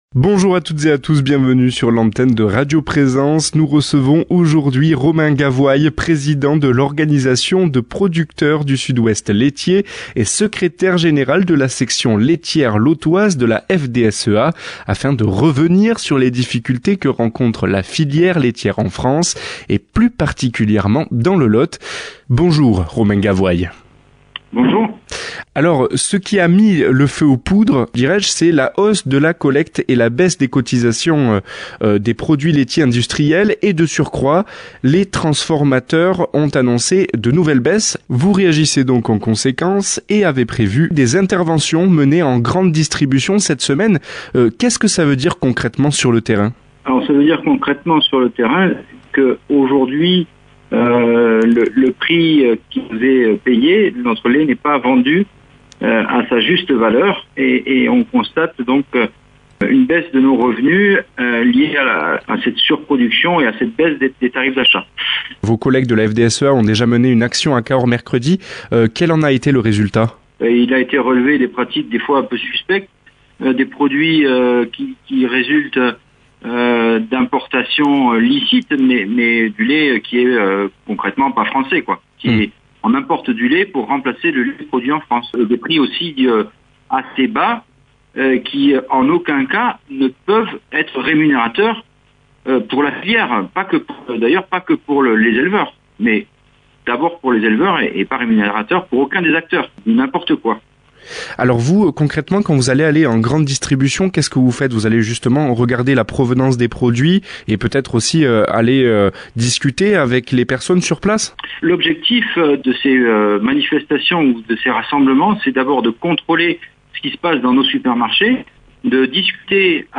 qui reçoit par téléphone